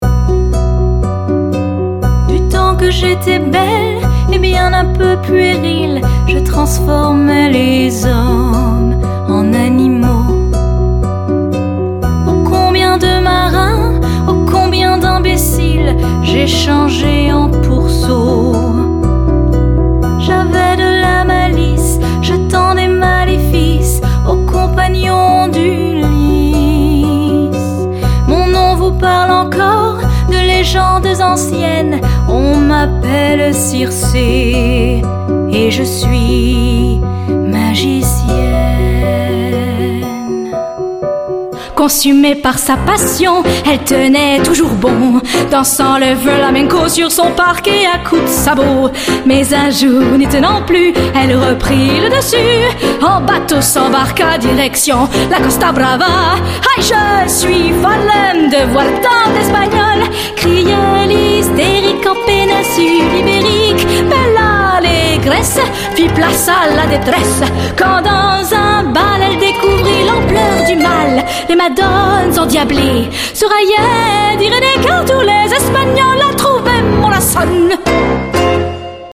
French Singing Showreel
Female
Warm